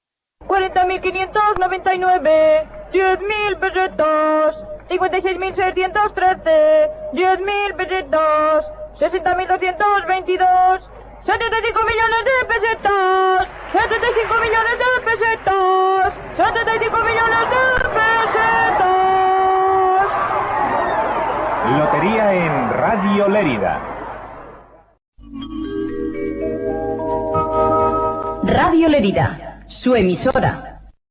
Promoció del programa dedicat a la Rifa de Nadal, indicatiu de l'emissora.